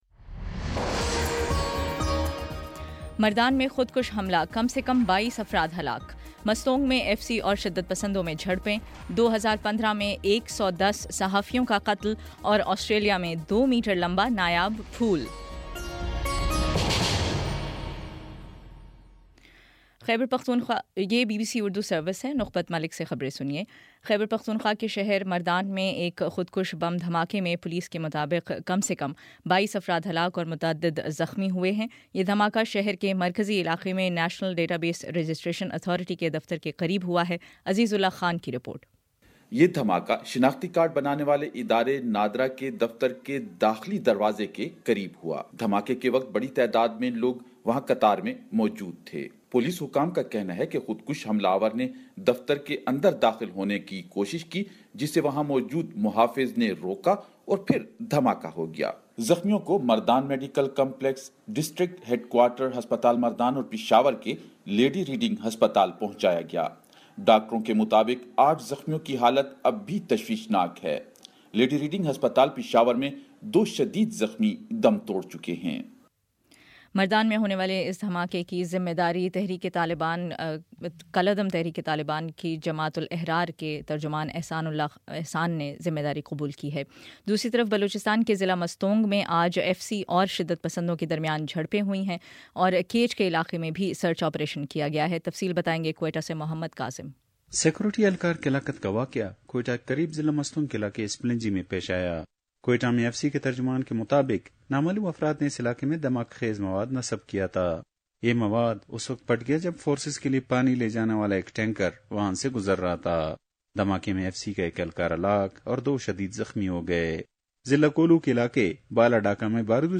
دسمبر 29 : شام چھ بجے کا نیوز بُلیٹن